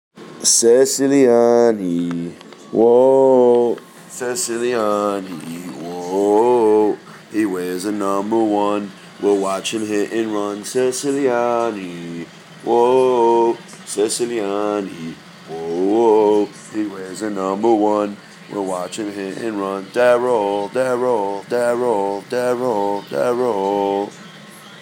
ceciliani chant